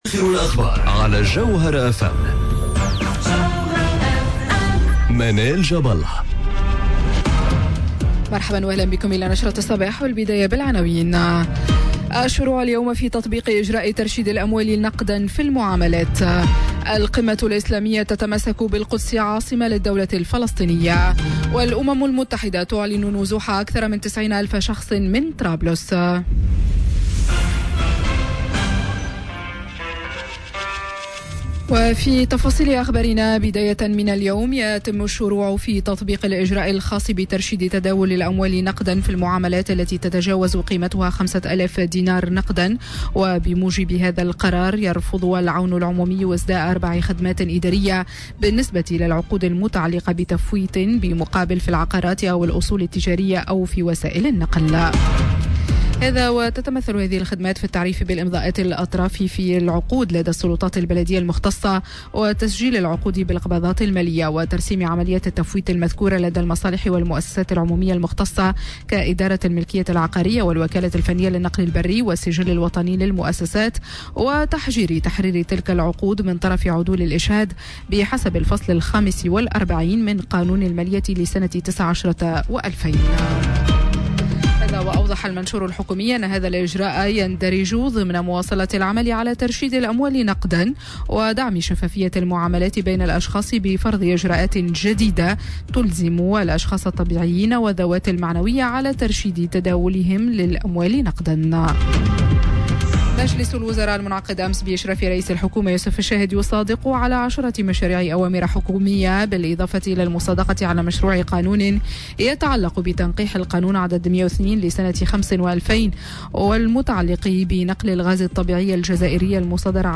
نشرة أخبار السابعة صباحا ليوم السبت 01 جوان 2019